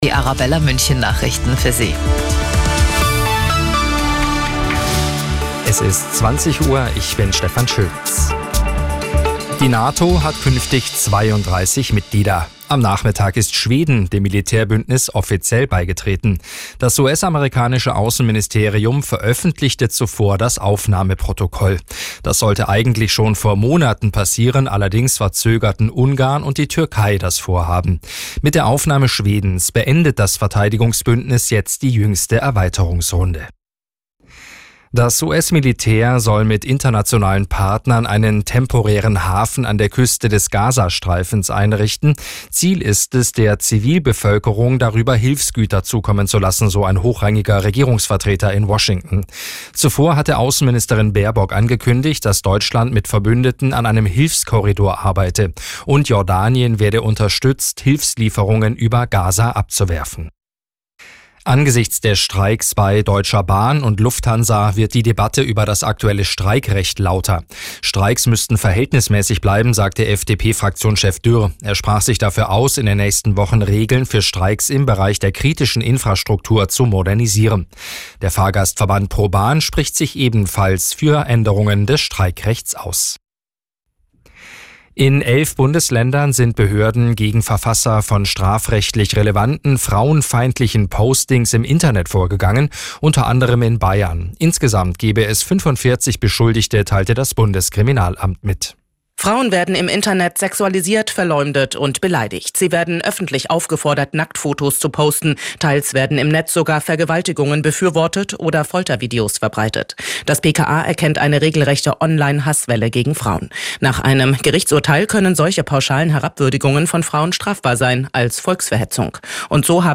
Die aktuellen Nachrichten von Radio Arabella - 08.03.2024